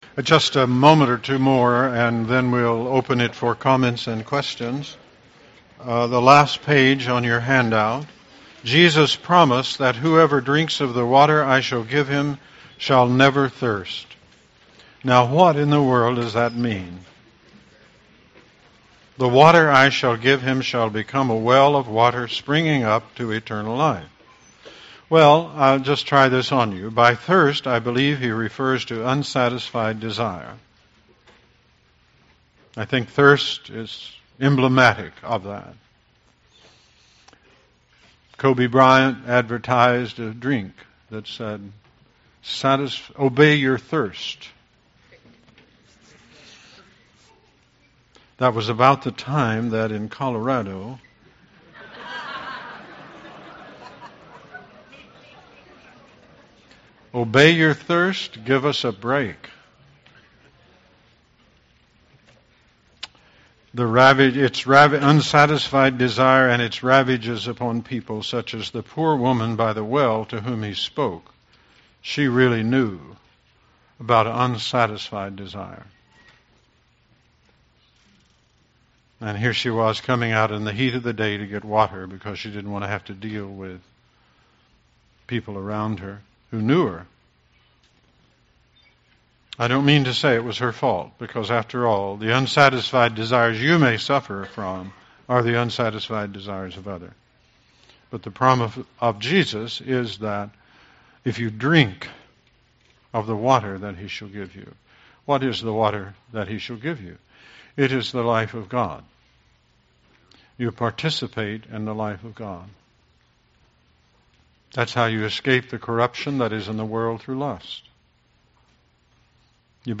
September 23, 2008Christian Spirituality and Soul Care Lecture Series